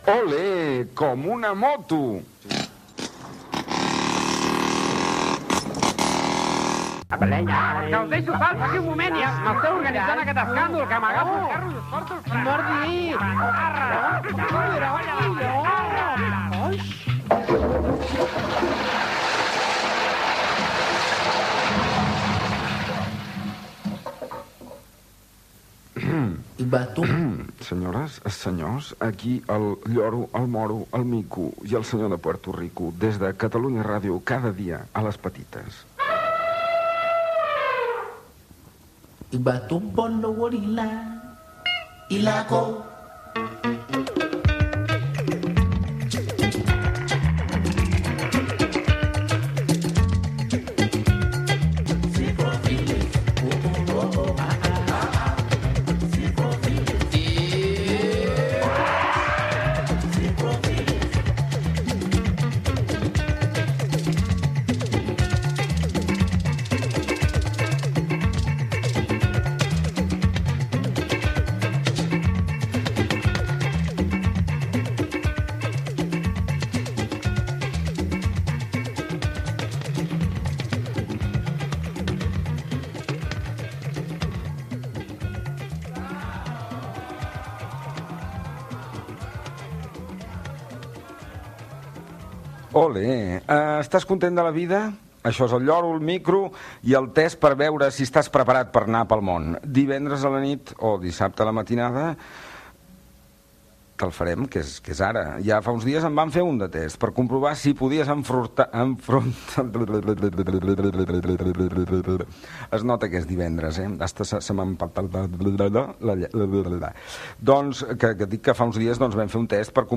Presentació, tema musical, comentari sobre un test fet al programa passat, tema musical, regles del joc del programa, tema musical, primera pregunta del joc , tema musical
Entreteniment